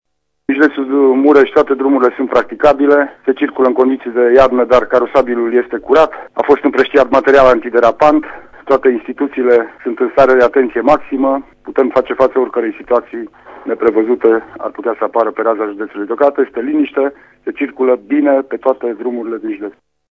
În judeţul Mureş se circulă în condiţii de iarnă, dar nu sunt probleme deosebite, spune prefectul Lucian Goga: